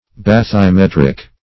Bathymetric \Bath`y*met"ric\, Bathymetrical \Bath`y*met"ric*al\,